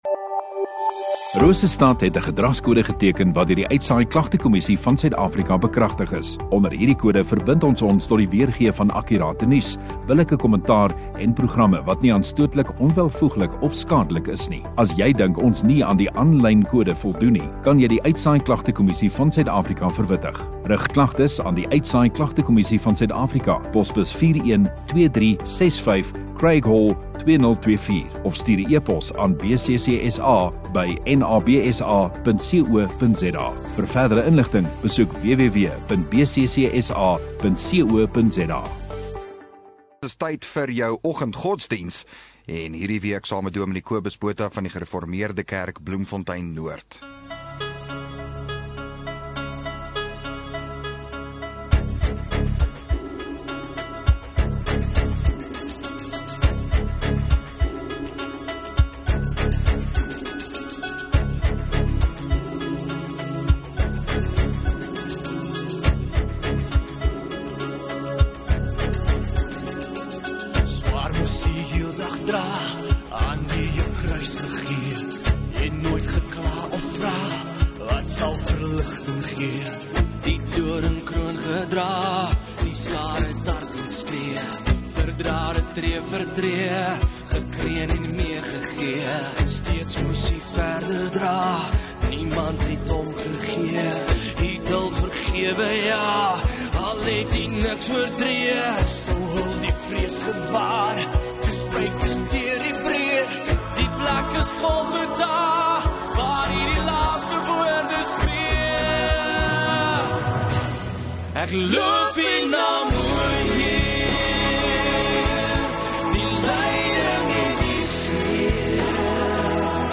22 Sep Maandag Oggenddiens